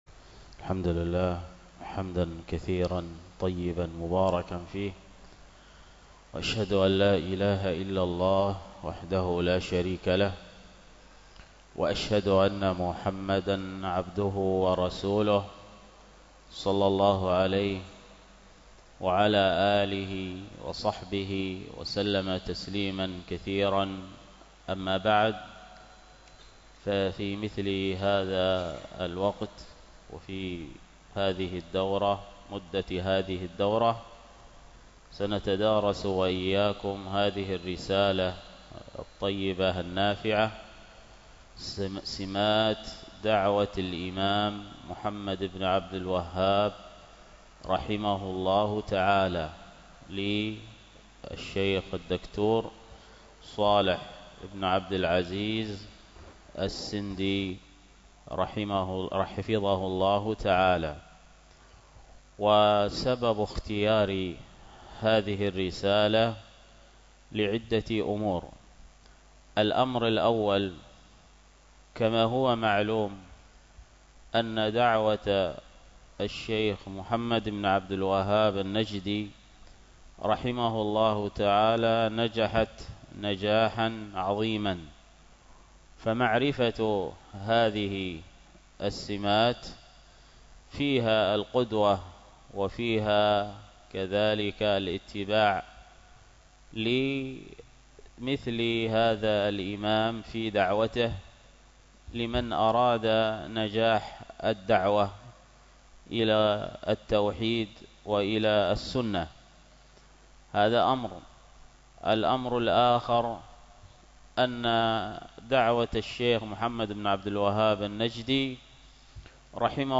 الخطبة